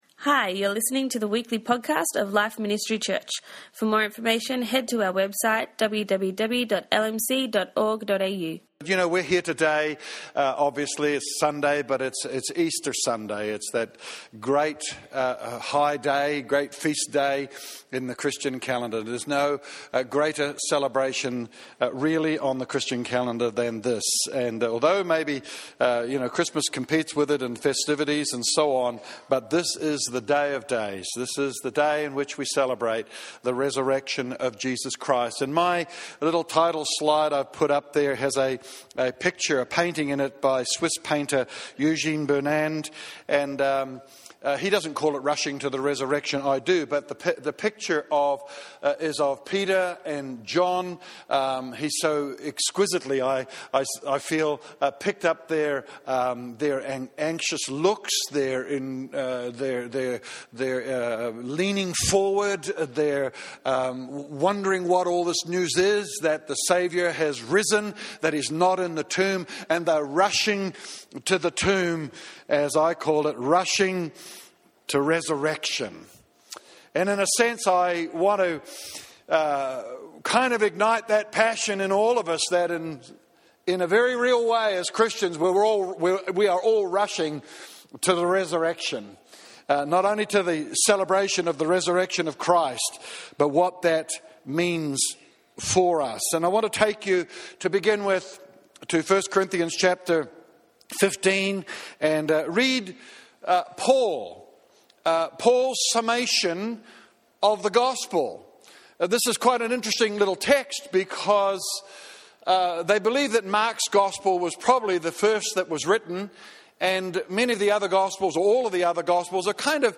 He shared at our Easter services on the necessary centering that Easter gives us as followers of Jesus. Forgiveness and Restoration.